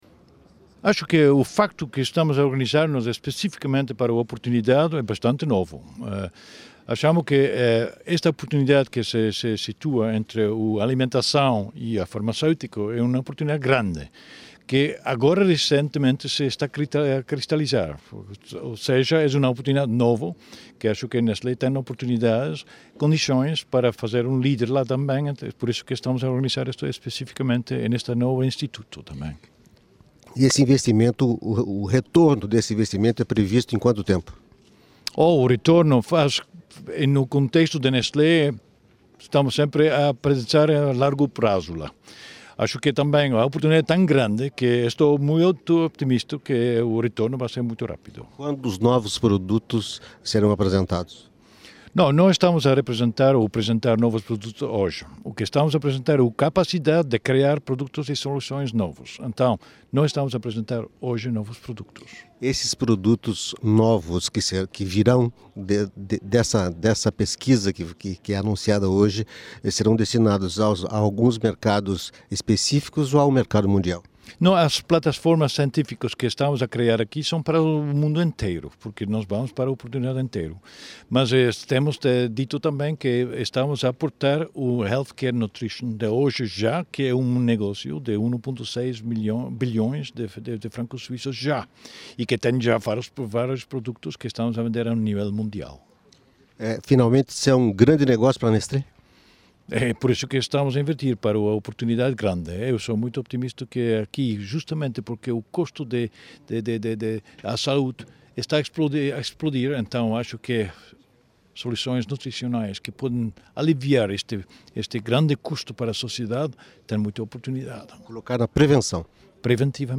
Paul Bulcke, diretor-geral da Nestlé, fala de investimentos a longo prazo com o novo instituto de pesquisa.